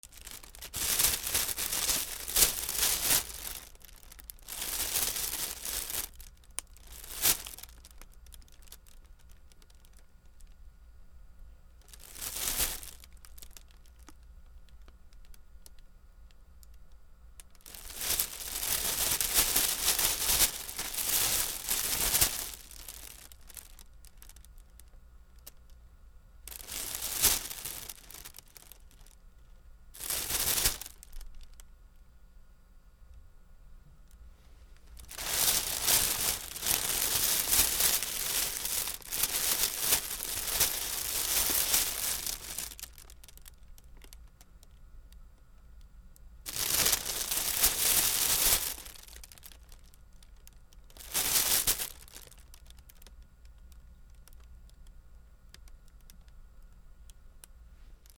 スーパーのビニール袋
C414